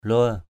/lʊə:/ (đg.) ghẹo, chọc = taquiner. tease. lue kamei l&^ km] chọc gái = taquiner les filles. tease girls. lue padrâh l&^ pd;H đùa dai. lue ngar l&^ ZR chọc...